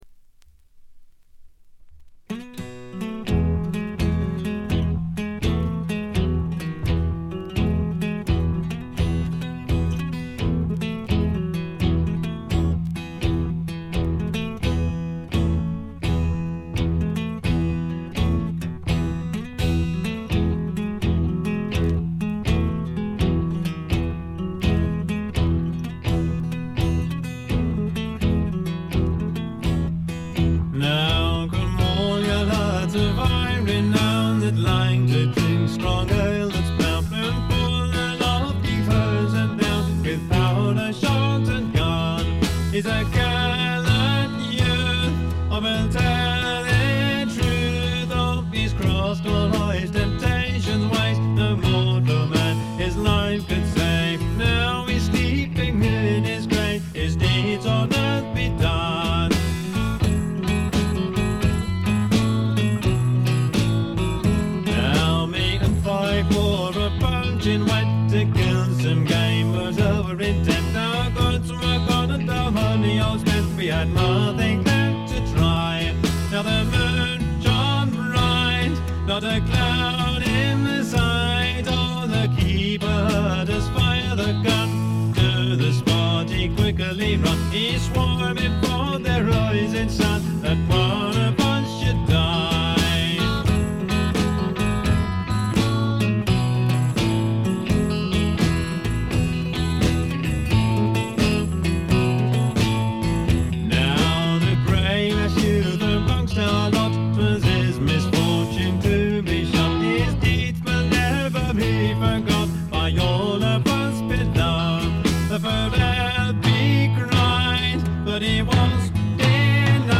エレクトリック・トラッドの基本中の基本です。
試聴曲は現品からの取り込み音源です。